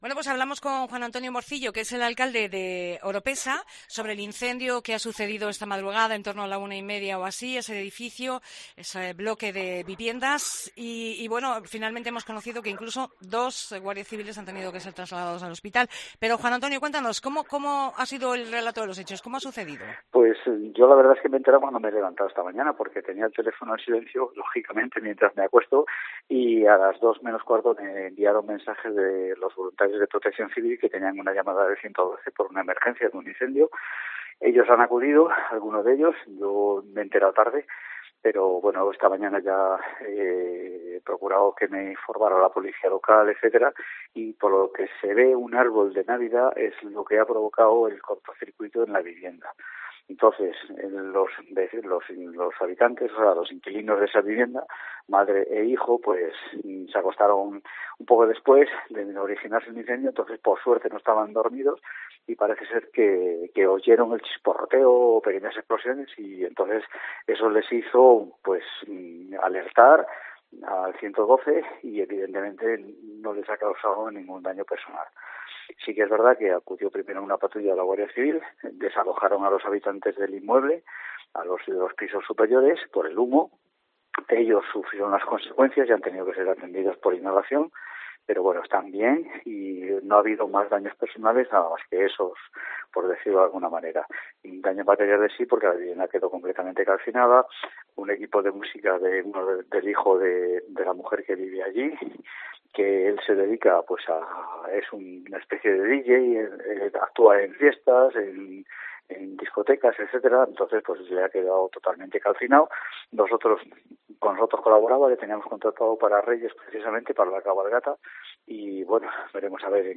Entrevista a Juan Antonio Morcillo, alcalde de Oropesa